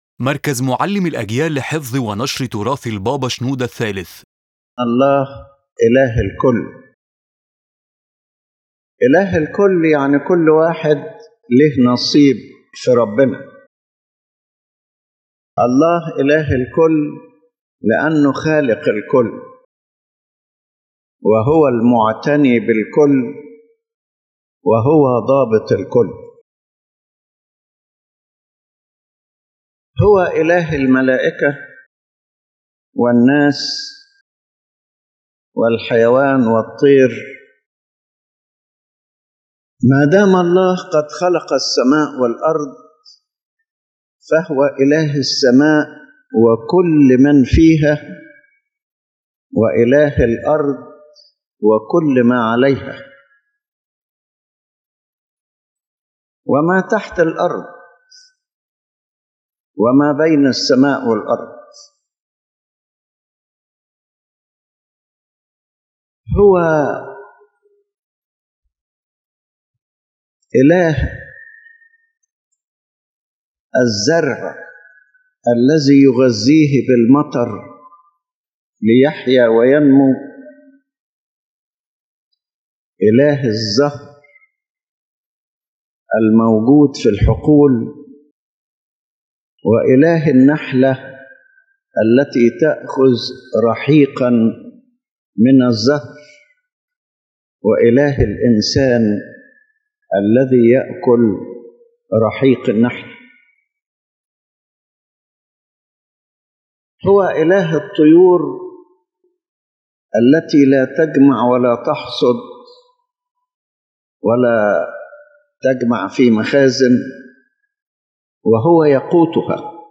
His Holiness Pope Shenouda III explains that God is the God of everyone without exception, for He is the Creator, Sustainer, and Caretaker of all creation.